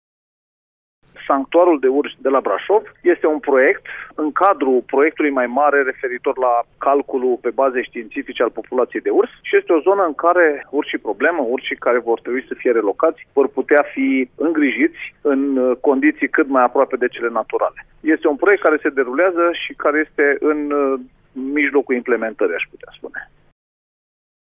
Secretarul de Stat la ministerul Mediului, apelor și Pădurilor, Ionuț Banciu: